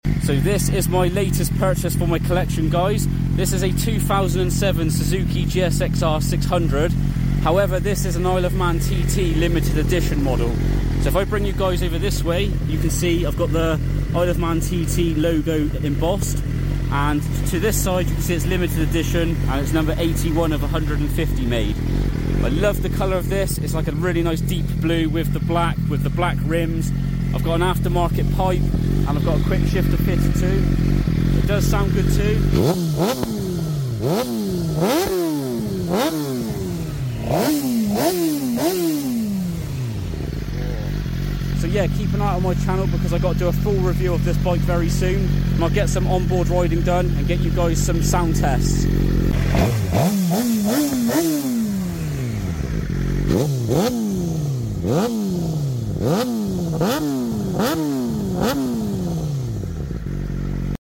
Sizuki GSX R 600 K7 sound effects free download
Sizuki GSX R 600 K7 Isle of man TT Limited Edition number 081/150 made walkaround exhaust sound